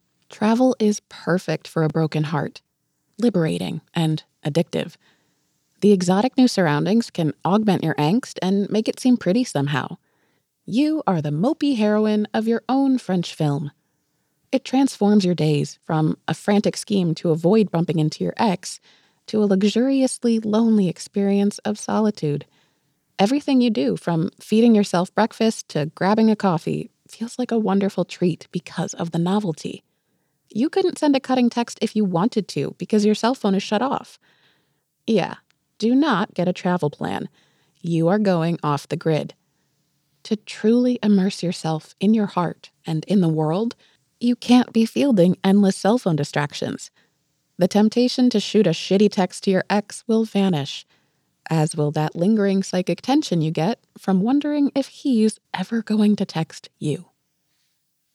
Nonfiction Memoir
nonfiction-memoir-sample.wav